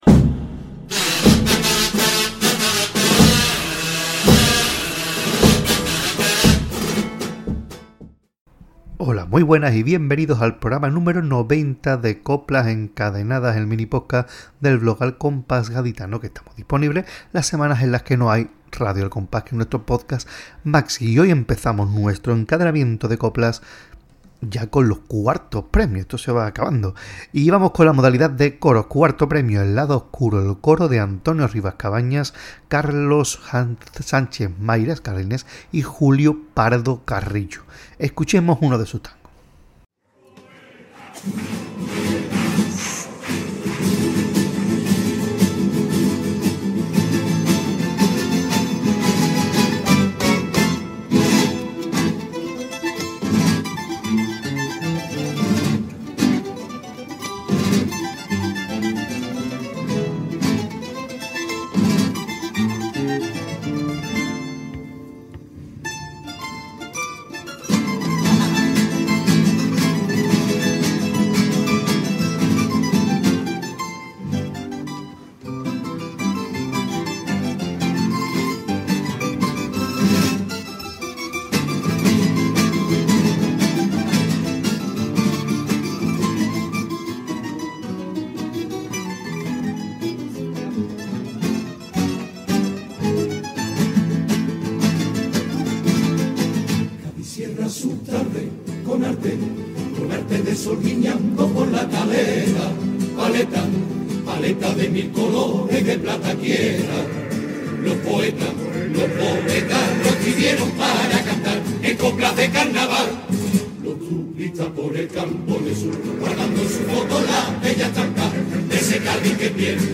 Tango
Pasodoble